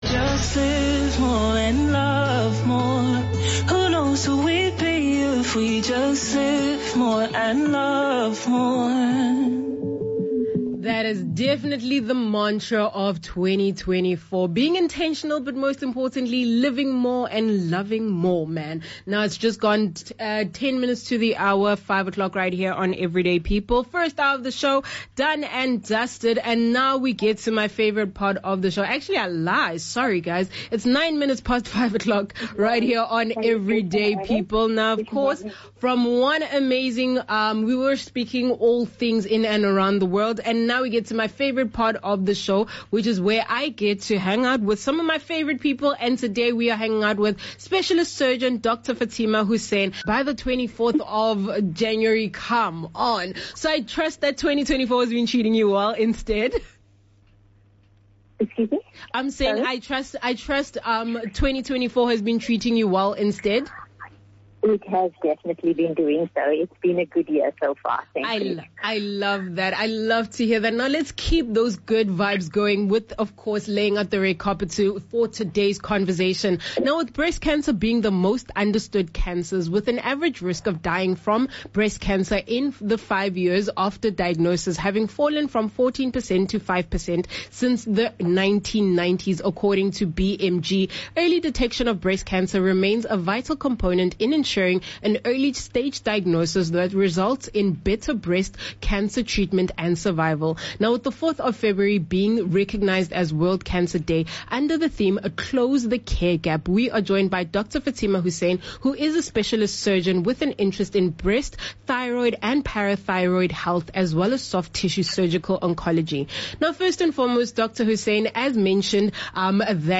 With kind permission from Bush Radio, the full interview is here: